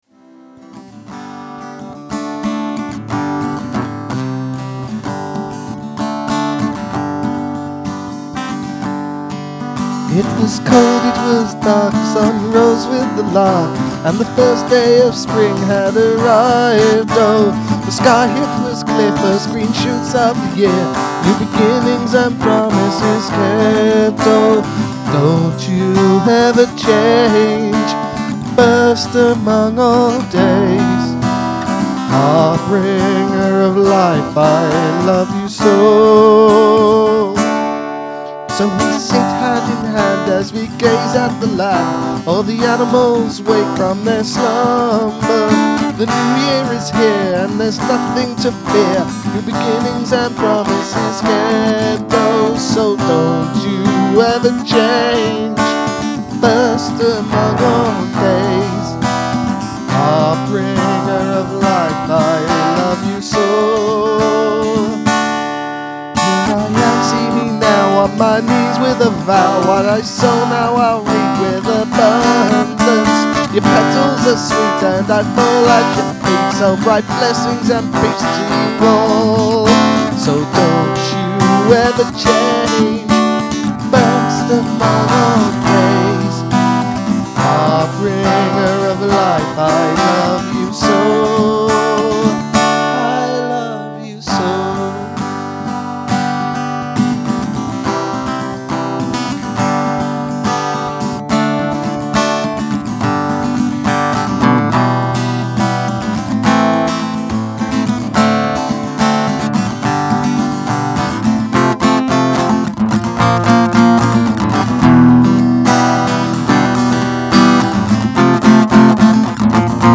Pagan Folk
Folk